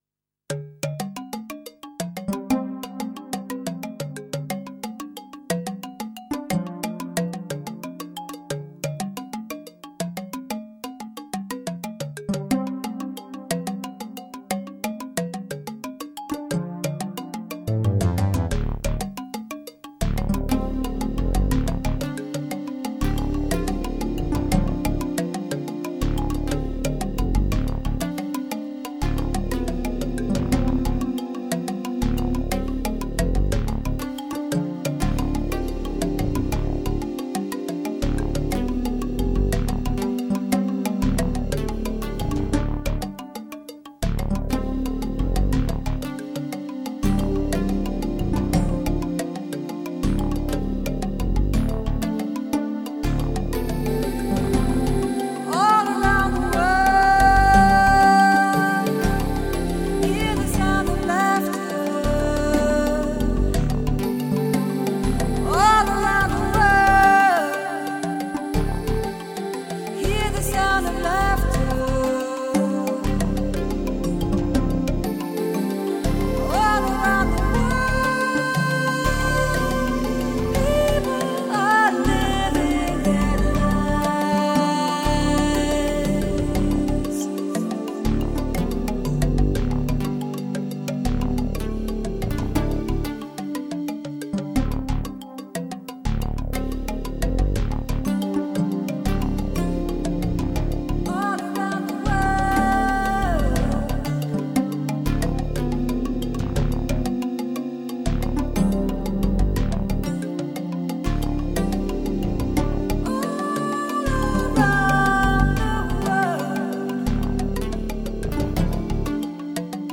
minimalistic lyrical content
sensuous melodies and hypnotic rhythm patterns